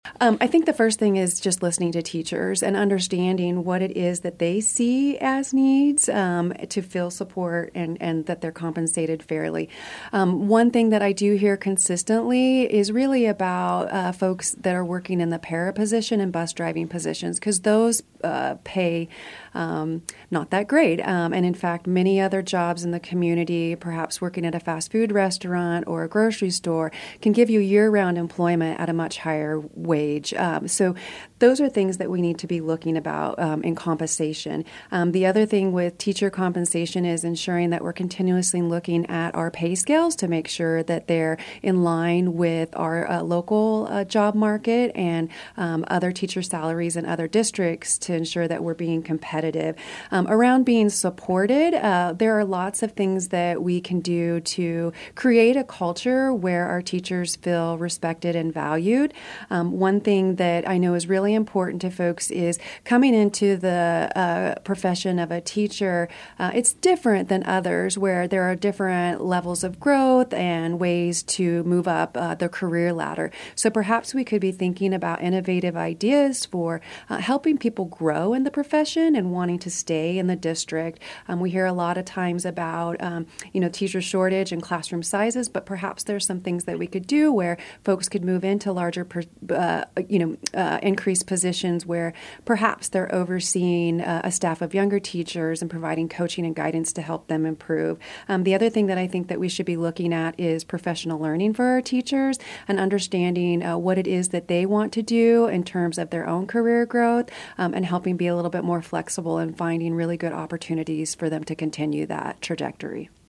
News Radio KMAN has invited candidates seeking a seat on the Manhattan City Commission and Manhattan-Ogden USD 383 school board to be interviewed ahead of Election Day.